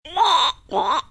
Cartoon Frog Sound Button - Free Download & Play